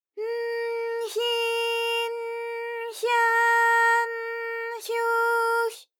ALYS-DB-001-JPN - First Japanese UTAU vocal library of ALYS.
hy_n_hyi_n_hya_n_hyu_hy.wav